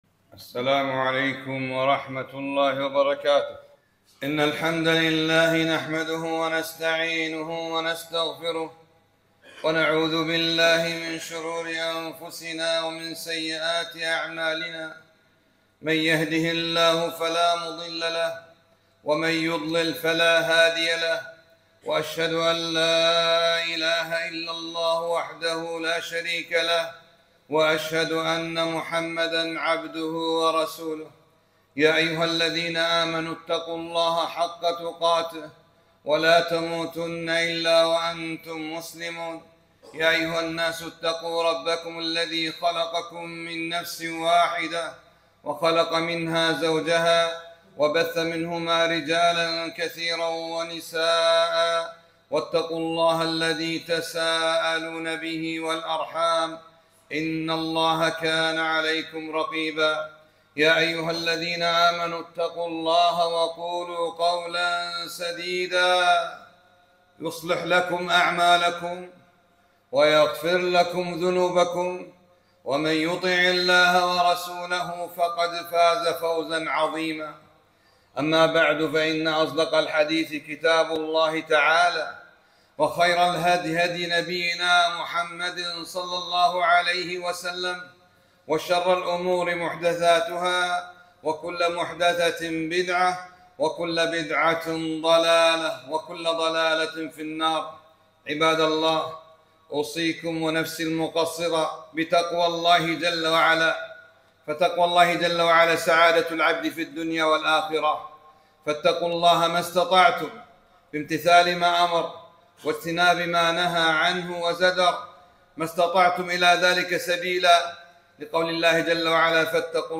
خطبة - (لا تَحقِرَنَّ مِنَ المَعْرُف شَيْئًا)